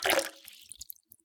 water10
Category 🌿 Nature
bath bubble burp click drain dribble drip dripping sound effect free sound royalty free Nature